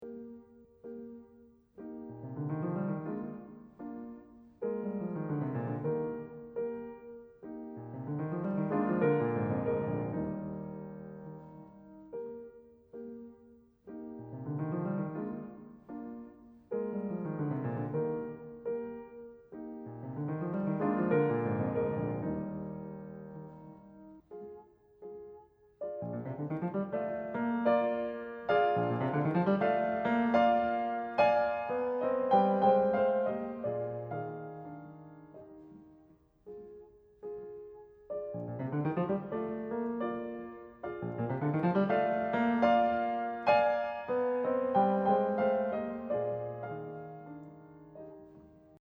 The piece starts in an almost neutral character.
This pattern repeats so that it becomes a rhythmic motive. Short-short-looong. Short-short-looong.